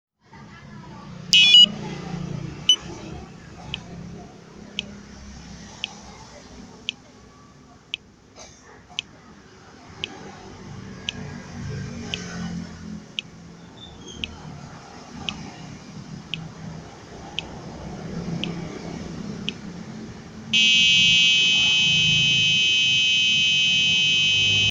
340uh 2 ohm, no shield Just for test....
This is the coil error sound.